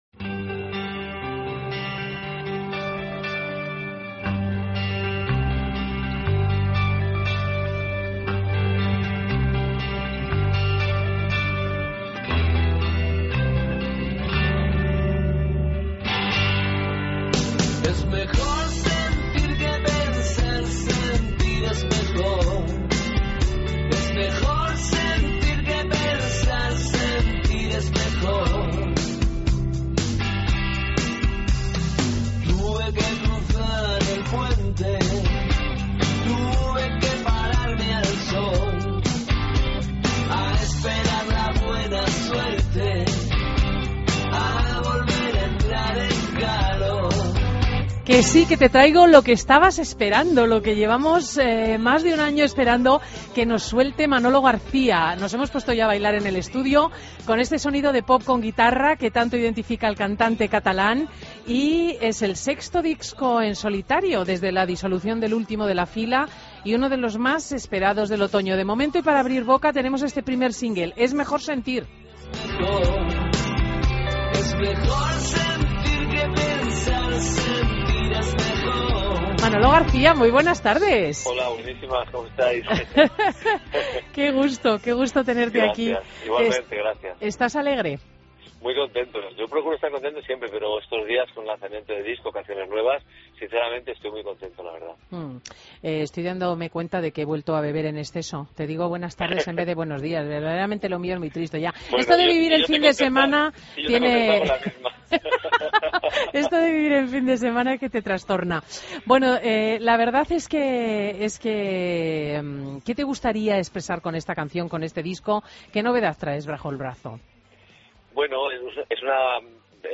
AUDIO: Entrevista a Manolo García en Fin de Semana COPE